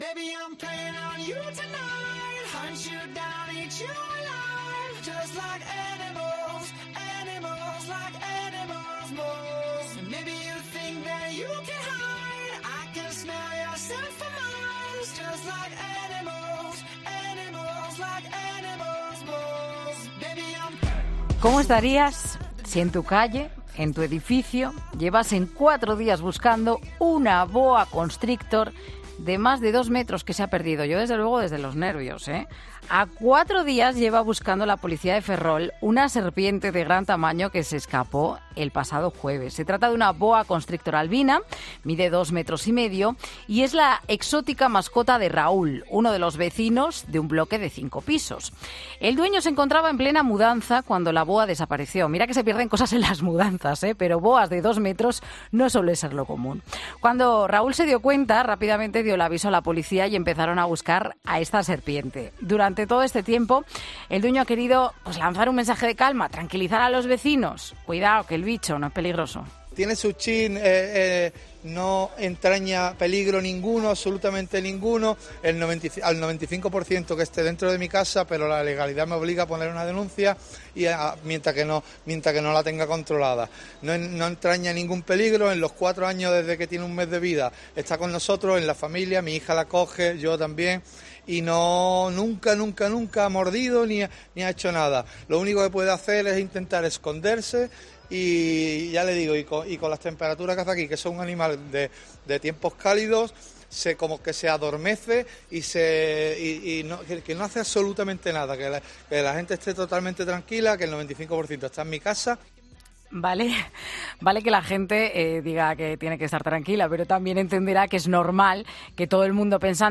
En 'Mediodía COPE' hablamos con uno de esos vecinos que nos cuenta por qué viven con miedo y por qué no pueden sentarse tranquilos cada vez que van...